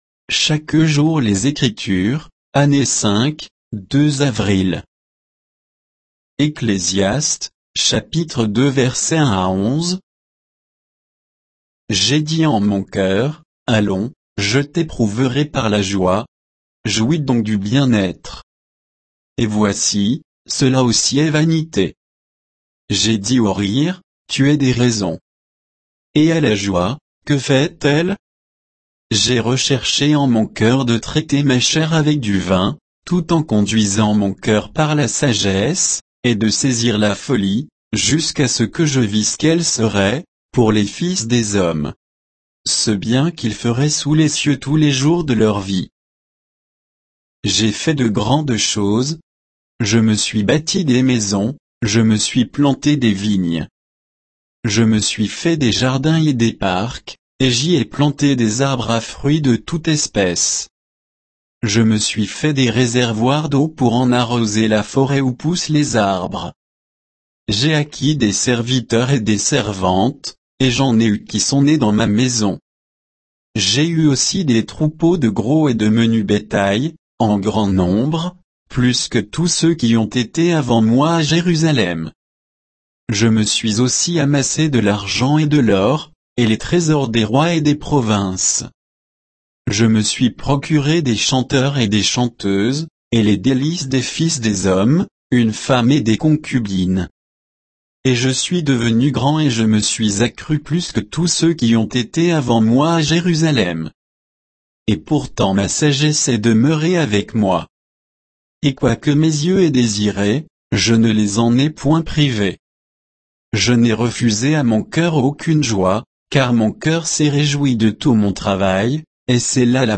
Méditation quoditienne de Chaque jour les Écritures sur Ecclésiaste 2